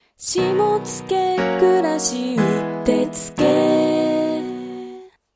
キャッチコピーなどのフレーズを、耳に残るメロディーや効果音などを添えて伝えるものです。